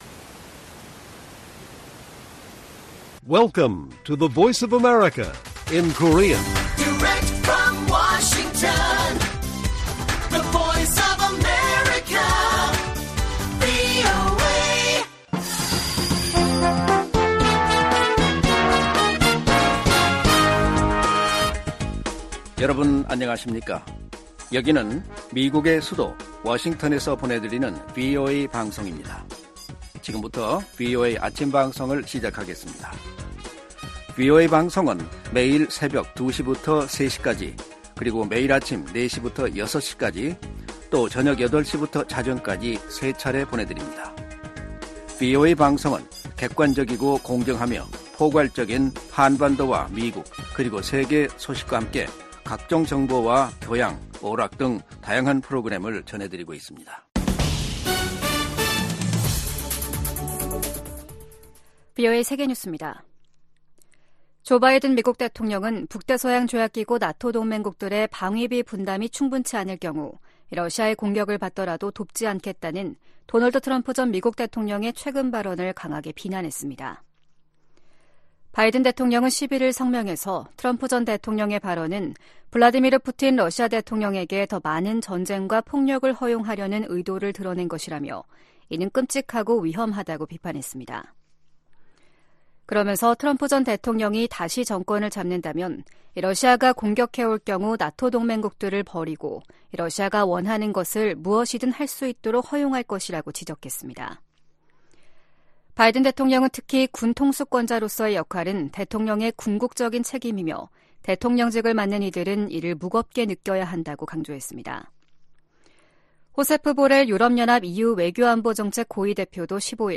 세계 뉴스와 함께 미국의 모든 것을 소개하는 '생방송 여기는 워싱턴입니다', 2024년 2월 13일 아침 방송입니다. '지구촌 오늘'에서는 이스라엘군이 가자지구 라파를 공격한 가운데 인질 2명을 구출했다고 밝힌 소식 전해드리고, '아메리카 나우'에서는 정부가 우크라이나·이스라엘 등 지원 패키지가 미 상원 절차 투표를 통과한 이야기 살펴보겠습니다.